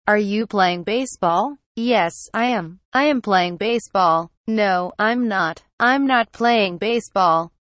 Conversation Dialog #1: